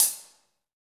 HH HH 330DR.wav